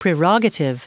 Prononciation du mot prerogative en anglais (fichier audio)
Prononciation du mot : prerogative